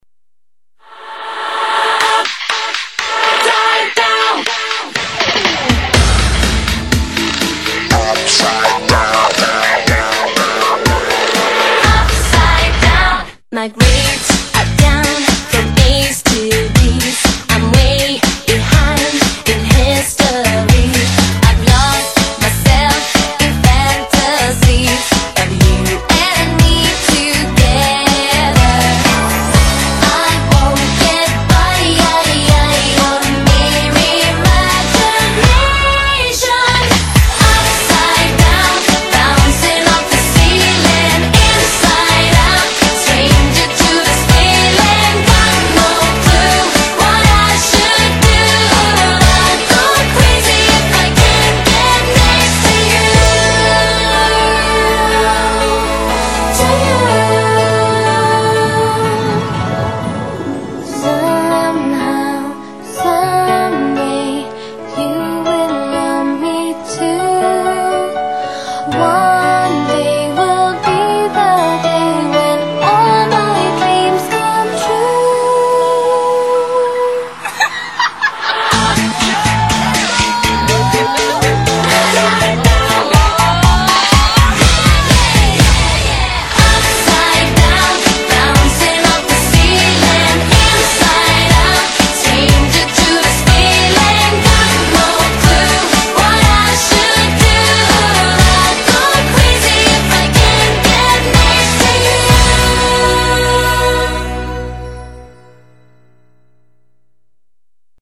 BPM122--1
Audio QualityPerfect (High Quality)